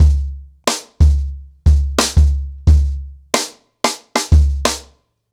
CornerBoy-90BPM.5.wav